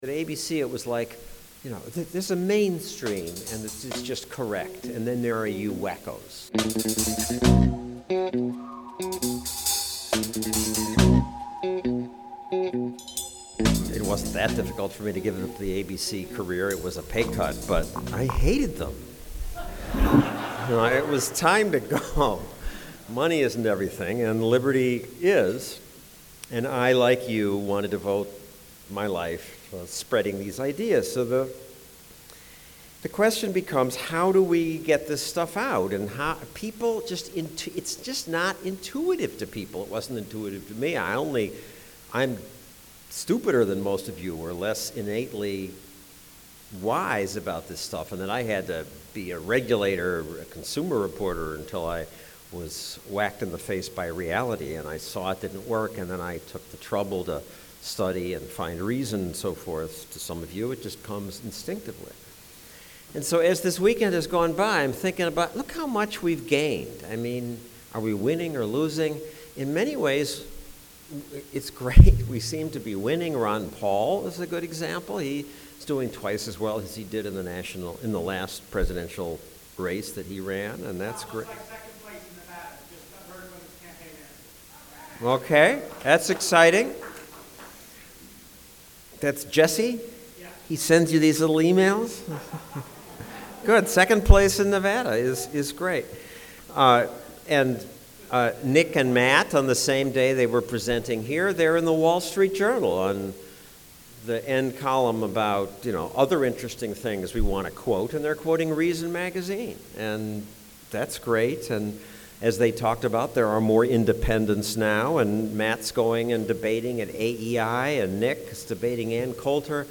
Stossel made a keynote speech at Reason Weekend 2012, Reason Foundation's annual donor event. He talked about why liberty is counter-intuitive to most people and how being accepted by conservatives more easily than by liberals surprised him. He also shared horror stories from his days working on 20/20 at ABC, where he says anchors like Peter Jennings would regularly look at him with disgust.